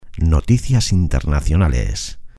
Locución gratuita para programas de televisión. Noticias internacionales.
noticias_internacionales_locucion_television_locutortv.mp3